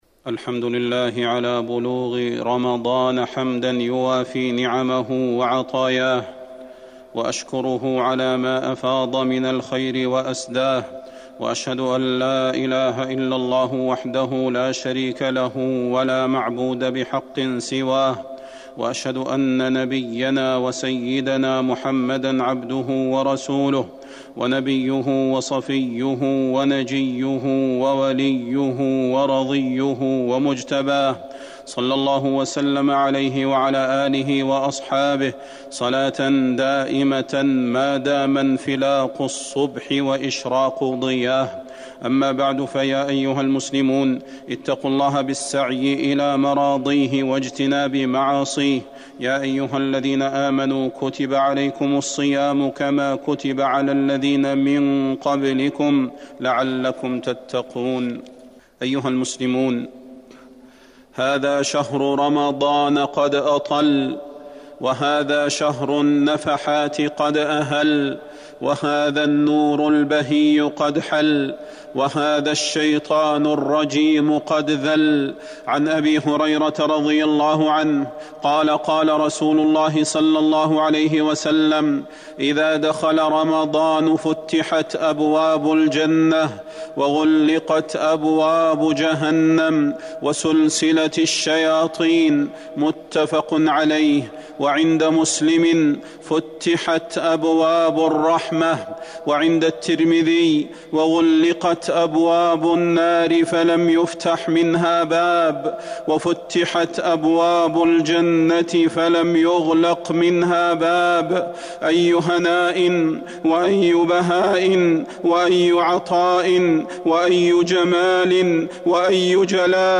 تاريخ النشر ١ رمضان ١٤٤١ هـ المكان: المسجد النبوي الشيخ: فضيلة الشيخ د. صلاح بن محمد البدير فضيلة الشيخ د. صلاح بن محمد البدير بشرى رمضان